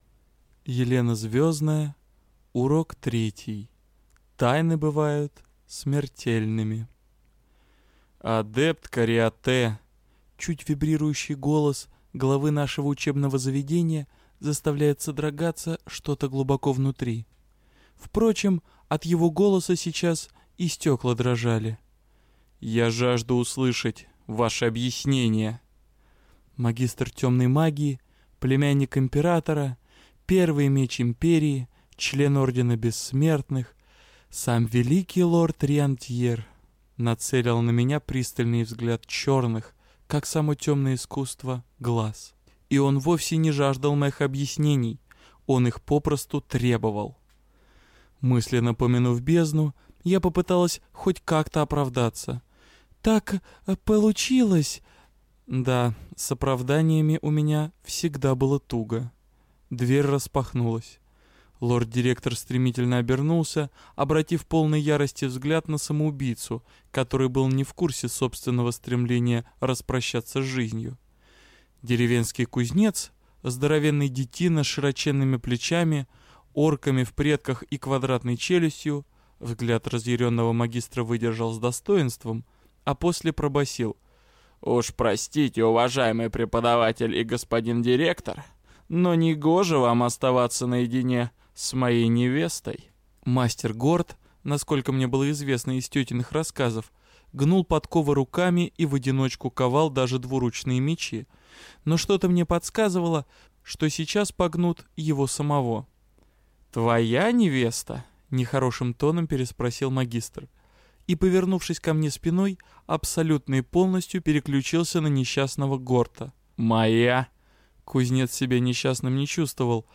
Аудиокнига Урок третий: Тайны бывают смертельными - купить, скачать и слушать онлайн | КнигоПоиск